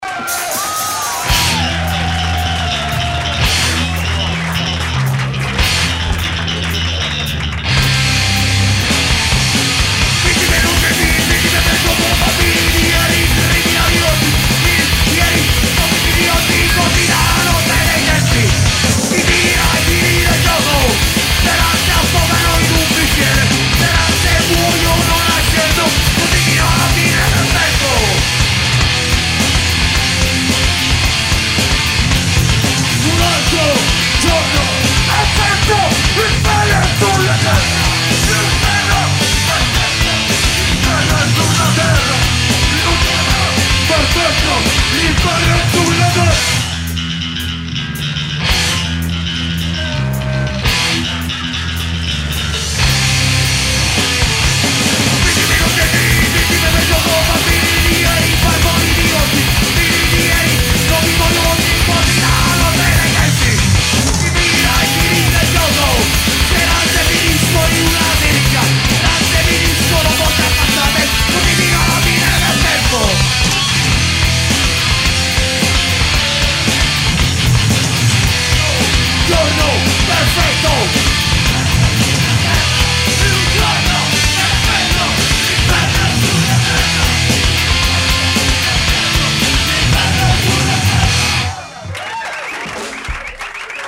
live in El Paso
dal vivo